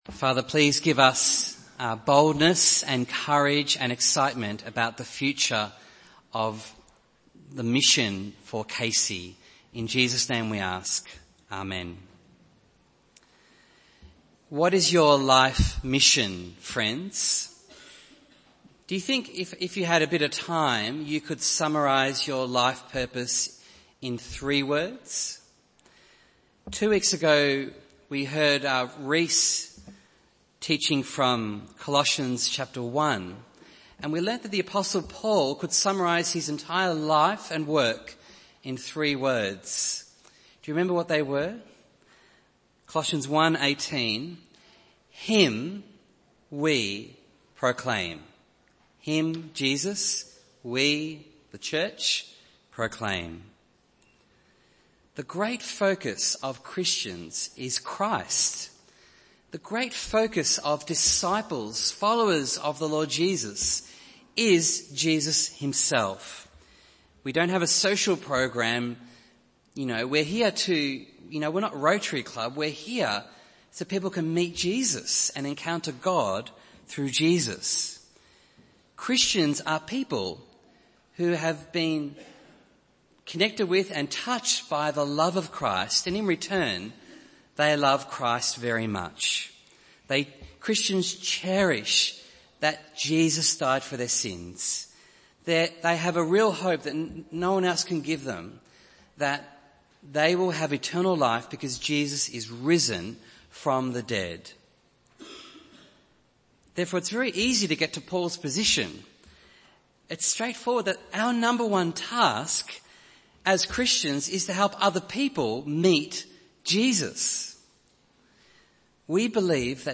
Bible Text: Acts 1:1-11 | Preacher